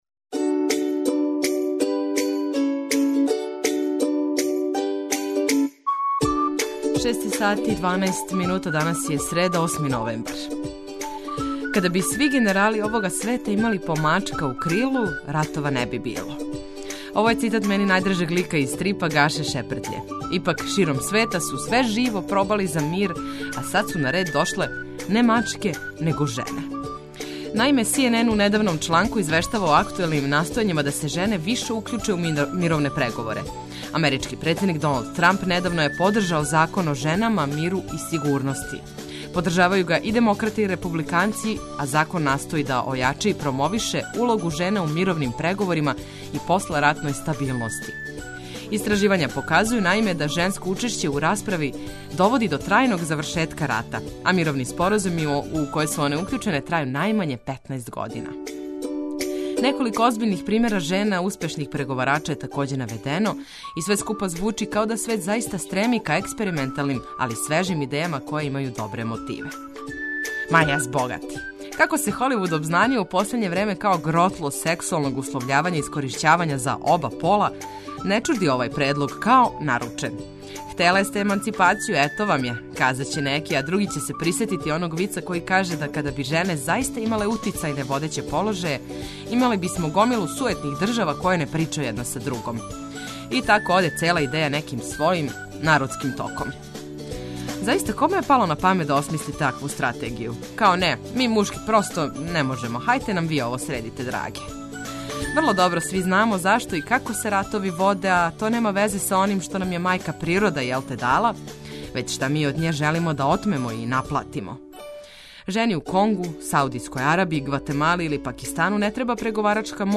Све важне информације су на једном месту да помогну у организовању дана, а ту су и омиљена музика и ведра екипа да вам улепшају прве сате јутра.